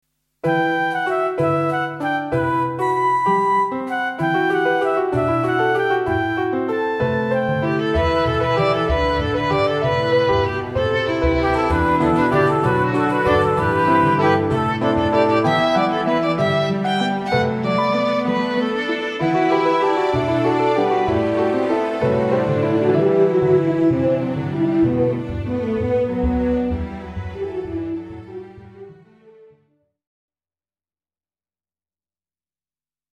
klassiek
Rustiek intermezzo, overgang naar volgende scene.